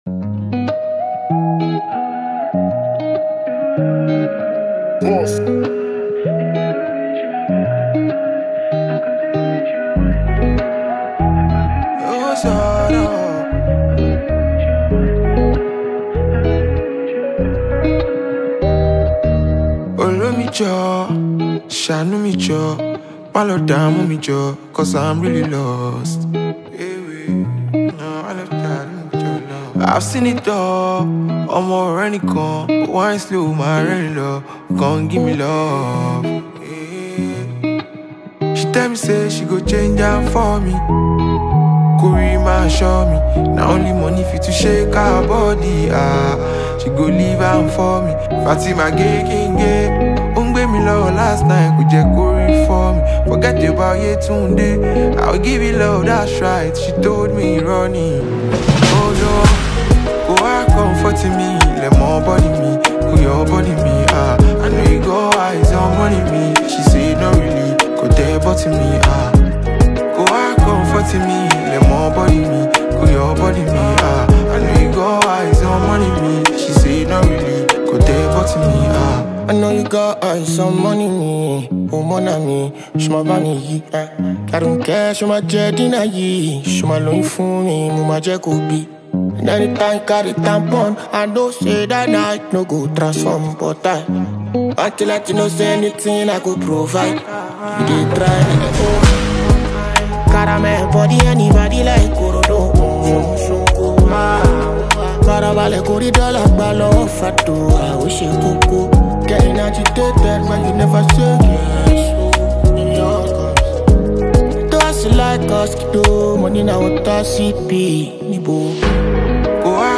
Afrobeat
deeply emotional and sonically rich record
brought to life by soulful melodies and infectious rhythms.